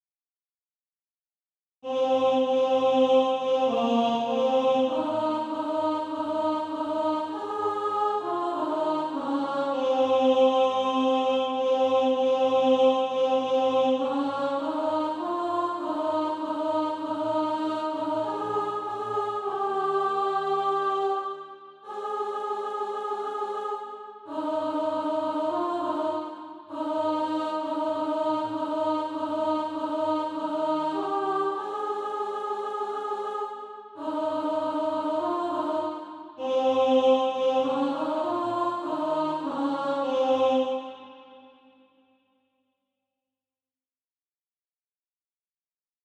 (SATB) Author
Practice then with the Chord quietly in the background.